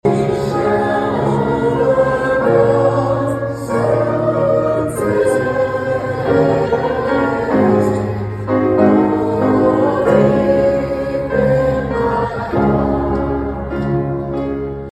To “remain committed to the change” was the theme for Sunday night’s rescheduled Martin Luther King Jr Celebration, held at First United Methodist Church and organized by the Eastside Community Group.